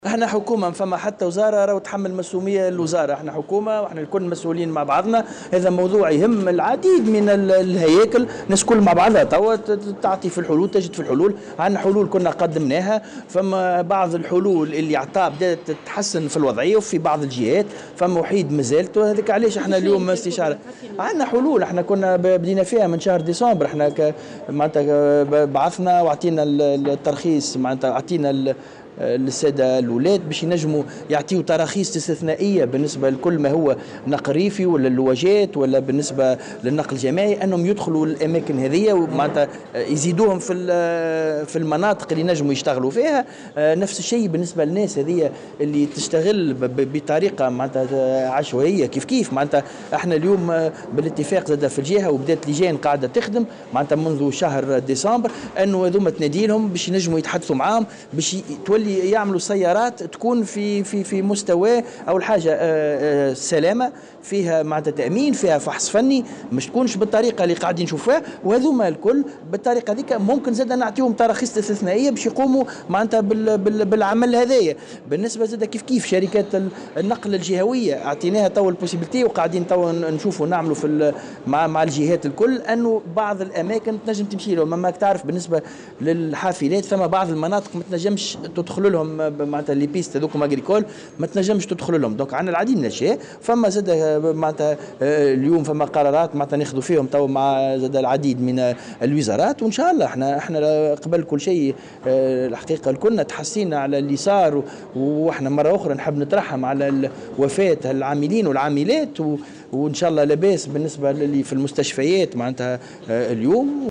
وأضاف في تصريح اليوم لمراسلة "الجوهرة أف أم" على هامش إشرافه على ورشة اختتام دراسة المخطط المديري الوطني للنقل، أنه تم تقديم جملة من الحلول منذ شهر ديسمبر الماضي منها منح تراخيص استثنائية لاصحاب سيارات النقل الريفي والجماعي لنقل العملة الفلاحيين خارج مناطق الجولان المرخص فيها ودعوة الشركات الجهوية للنقل إلى توفير حافلات تخصص لبعض المناطق والمسالك، إضافة إلى بعض القرارات الأخرى التي تعكف الحكومة حاليا على اتخاذها.